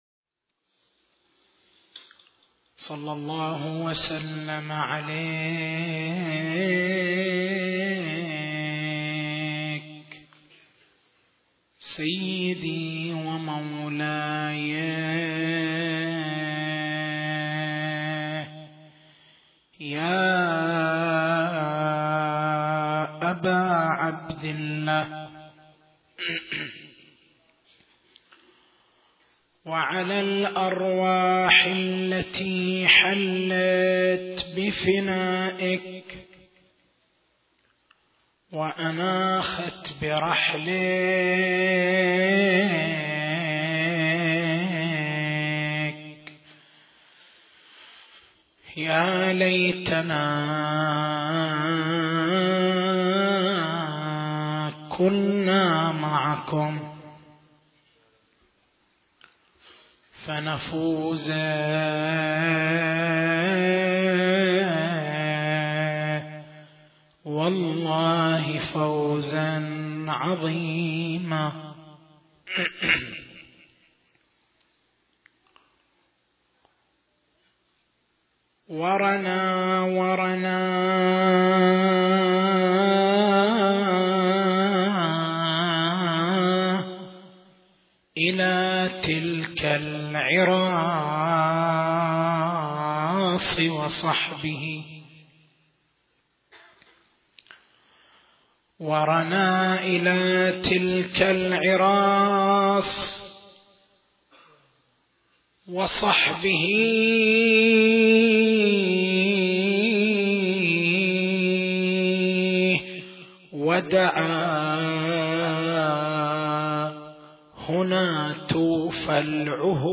تاريخ المحاضرة: 05/01/1432 نقاط البحث: الشبهة الأولى: نصوص نهج البلاغة تثبت أنّ أمير المؤمنين (ع) لم يكن راغبًا في الإمامة السياسيّة. هل كتاب نهج البلاغة صحيح بتمامه عند الشيعة؟